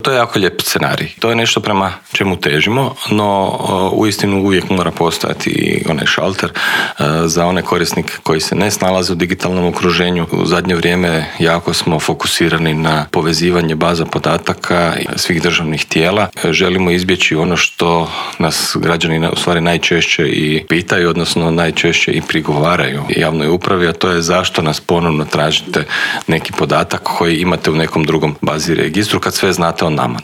ZAGREB - U Intervjuu tjedna Media servisa gostovao je državni tajnik u Središnjem državnom uredu za razvoj digitalnog društva Bernard Gršić koji nam je otkrio kako je proteklo prvo online samopopisivanje građana, koliko ljudi koristi sustav e-Građani, što sve taj sustav nudi, što planira ponuditi kao i koje su njegove prednosti.